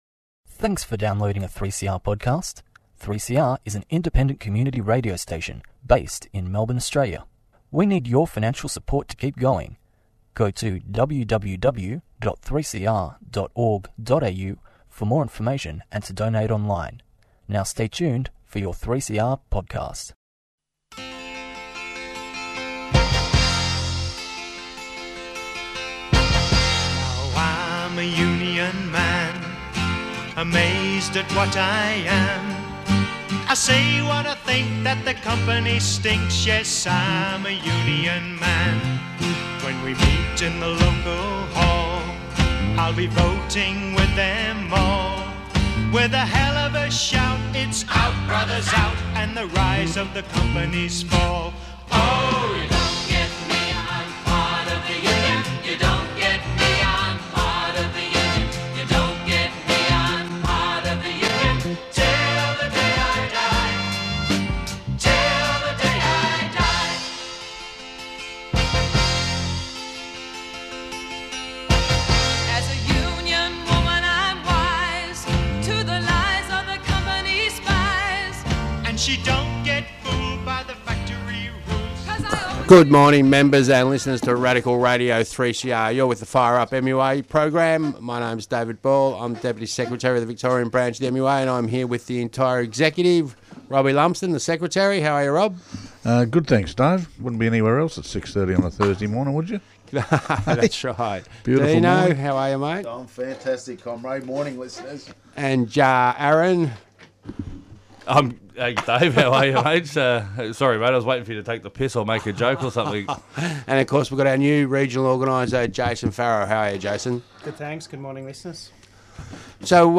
The 28th anniversary of the Patrick's Dispute with a discussion about the core issues and the outcomes from the dispute for the Union and workers today.